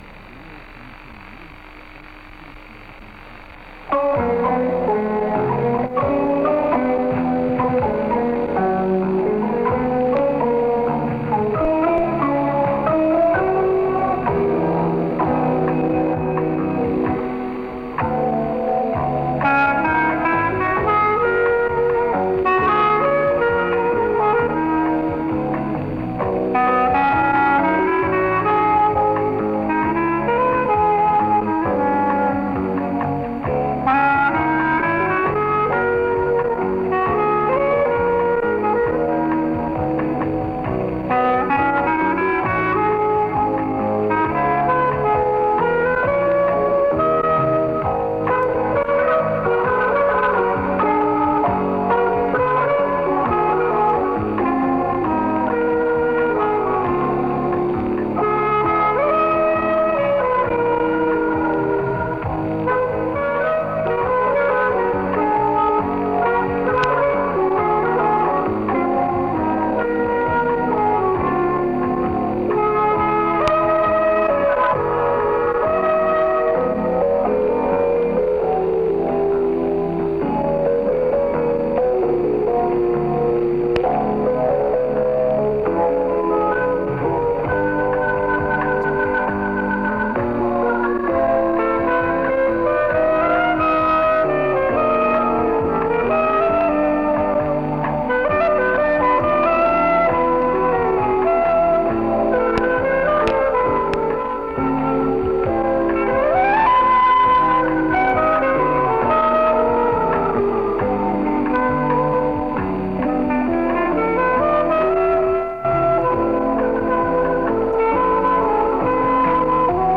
югославский кларнетист и клавишник